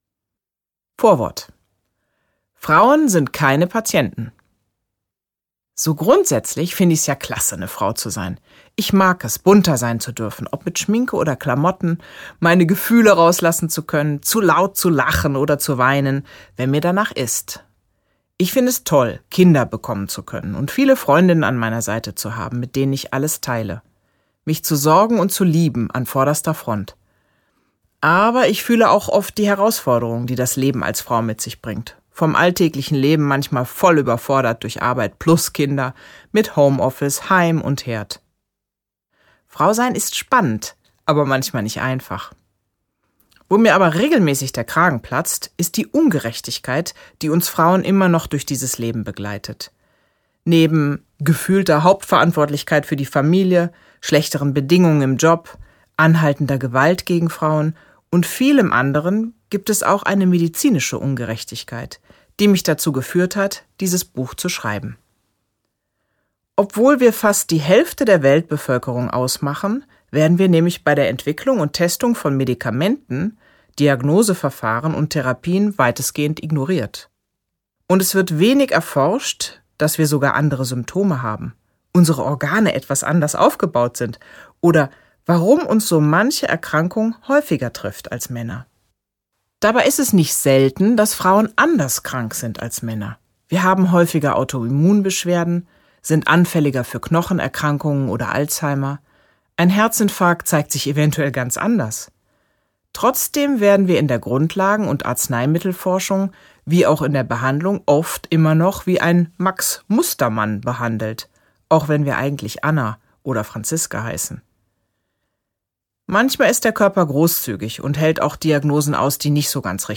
Gekürzt Autorisierte, d.h. von Autor:innen und / oder Verlagen freigegebene, bearbeitete Fassung.
Die bessere Medizin für Frauen Gelesen von: Dr. med. Franziska Rubin